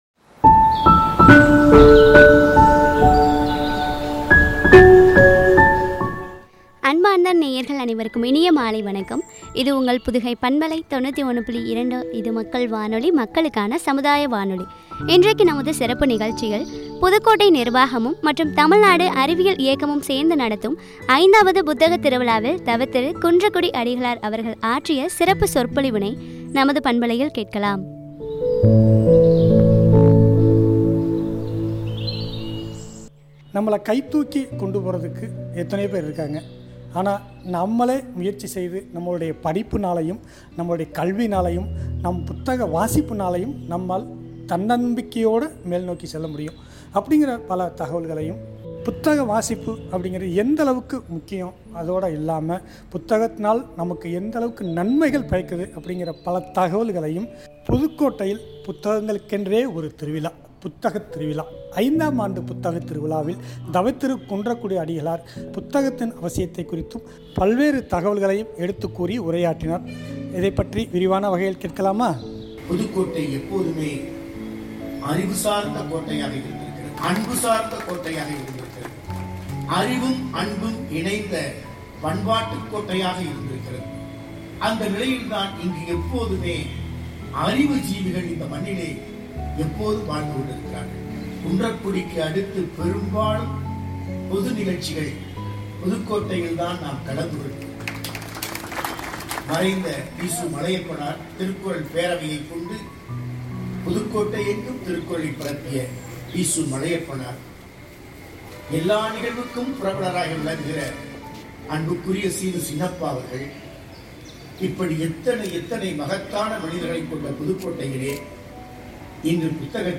புதுக்கோட்டை புத்தகத் திருவிழாவில் “தவத்திரு ” குன்றக்குடி அடிகளார் அவர்கள் ஆற்றிய சிறப்பு சொற்பொழிவு